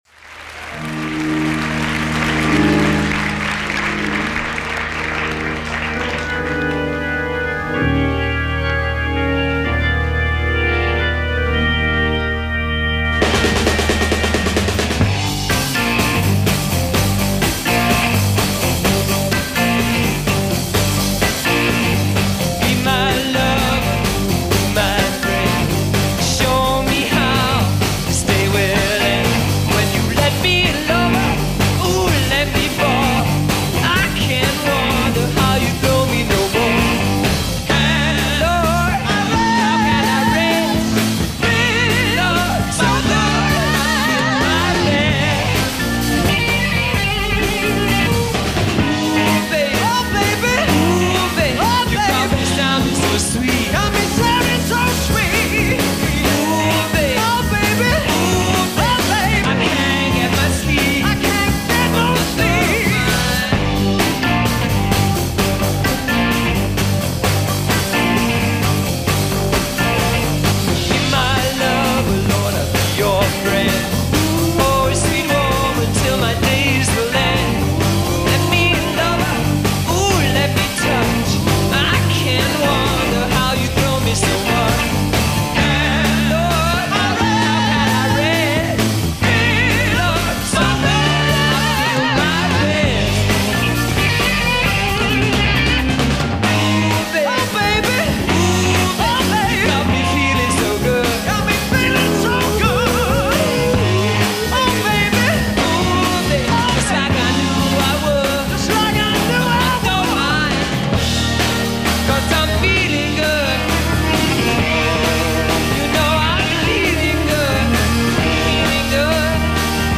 keyboards, bassist
drummer
guitarist